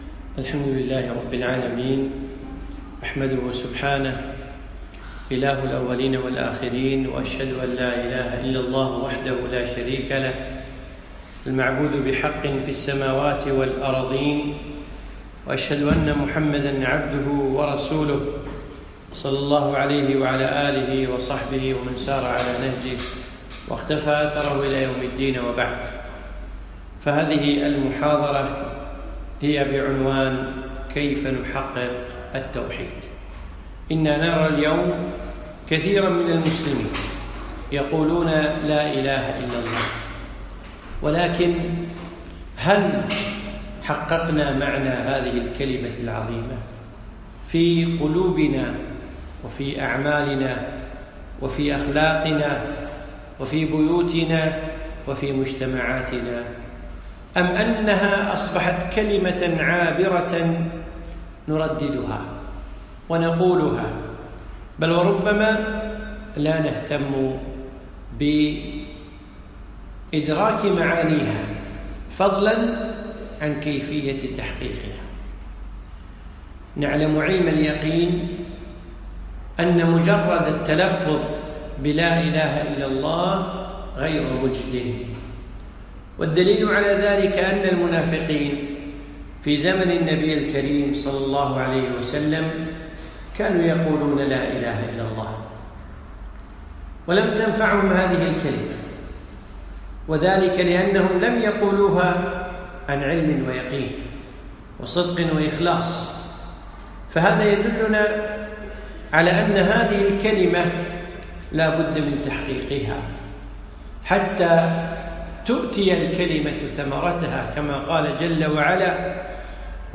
محاضرة - كيف نحقق التوحيد ؟